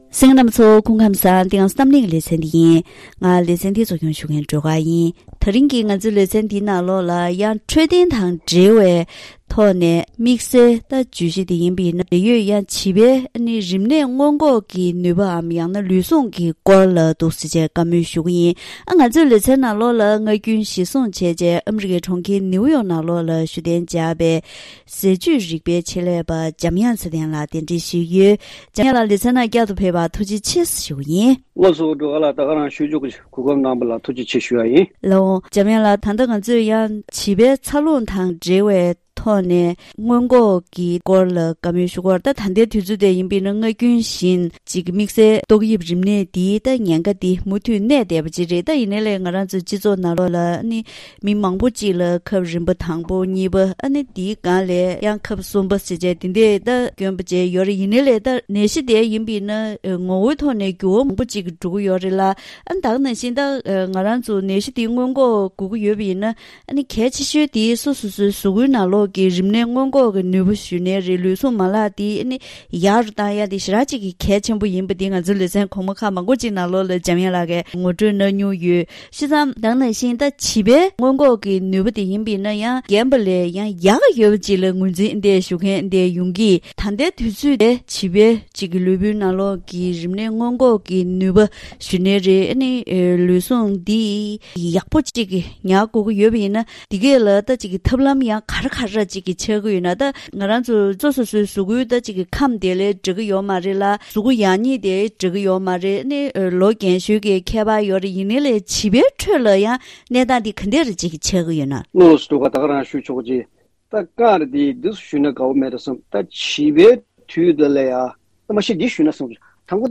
ད་རིང་གི་གཏམ་གླེང་ཞལ་པར་ལེ་ཚན་ནང་ཕྲུ་གུ་གསོ་སྐྱོང་གི་བརྒྱུད་རིམ་ནང་ཕ་མའི་བྱམས་སྐྱོང་དང་ཀུན་སྤྱོད་ཀྱི་སློབ་གསོ་ཕུད། གལ་ཆེ་ཤོས་ཤིག་ནི་ཉིན་རེའི་ཟས་བཅུད་ཚད་ལྡན་ཐོག་ནས་བྱིས་པའི་ལུས་པོའི་ནང་གི་རིམས་ནད་སྔོན་འགོག་གི་ནུས་པ་ཤུགས་རུ་གཏོང་རྒྱུ་འདི་ཕྲུ་གུའི་བདེ་ཐང་དང་ཐད་ཀར་འབྲེལ་བ་ཆགས་ཀྱི་ཡོད་པར་བརྟེན་ཉིན་རེའི་ཟས་དང་སྤྱོད་པའི་གོམས་གཤིས་བསྟེན་ཕྱོགས་སྐོར་ལ་ཟས་བཅུད་རིག་པའི་ཆེད་ལས་པ་དང་ལྷན་དུ་བཀའ་མོལ་ཞུས་པ་ཞིག་གསན་རོགས་གནང་།